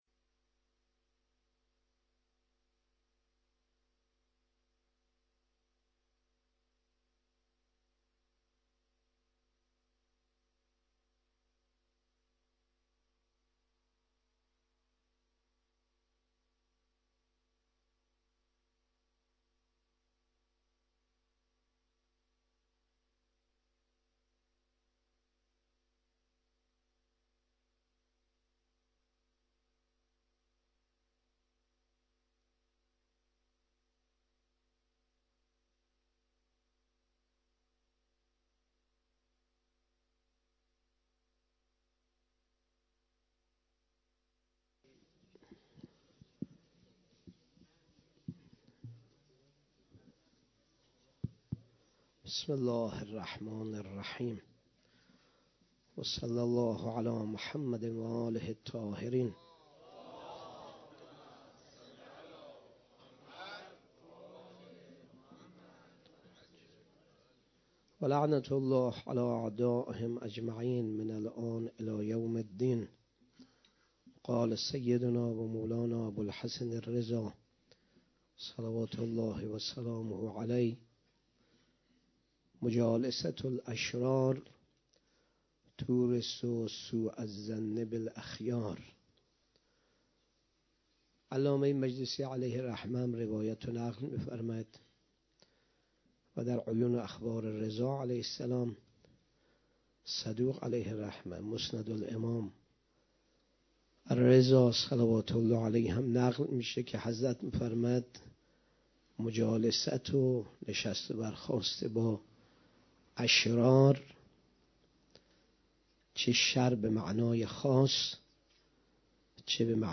30 صفر 96 - هیئت محبین حضرت زینب - سخنرانی